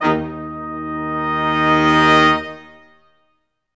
Index of /90_sSampleCDs/Roland LCDP06 Brass Sections/BRS_Quintet sfz/BRS_Quintet sfz